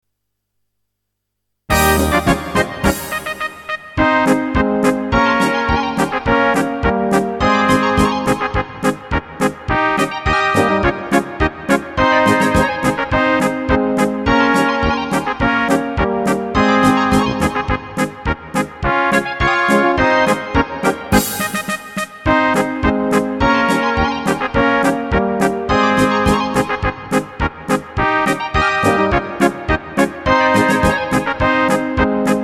Rubrika: Národní, lidové, dechovka
- polka
1,2; a PŘEDEHRA  2 + 30 + 30 + 2 takty